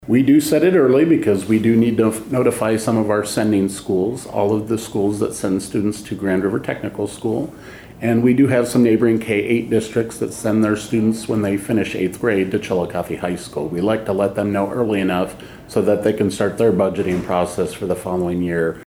The Chillicothe R-II School District Tuition rate for the 2026/27 school year was approved by the School Board at $11,201.16 per student per year.  Superintendent Dr Dan Wiebers says they need to set it well ahead for planning purposes.